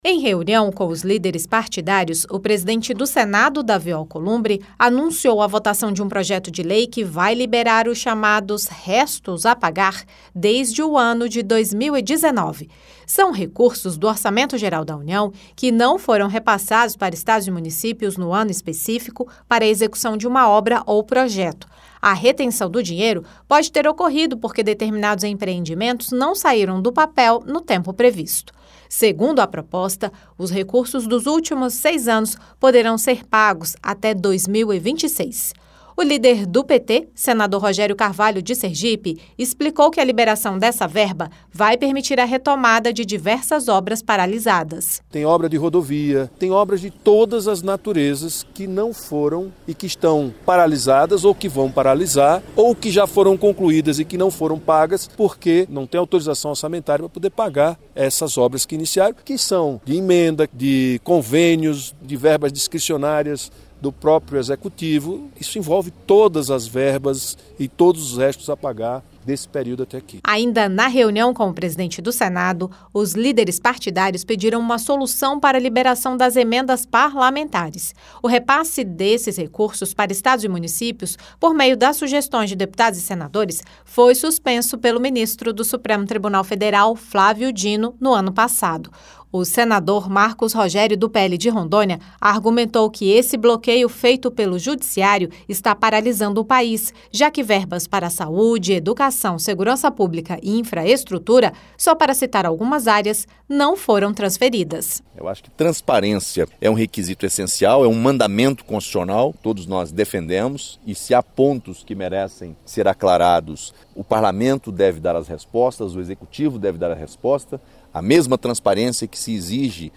Esses recursos do Orçamento não foram pagos no ano específico por conta de problemas na execução das obras nos estados e municípios. O líder do PT, senador Rogério Carvalho (SE), explicou que esse dinheiro vai permitir a retomada de diversas obras em todo o País.
O senador Marcos Rogério (PL-RO) argumentou que o Congresso Nacional já aprovou novas regras de transparência e rastreabilidade dos recursos das emendas.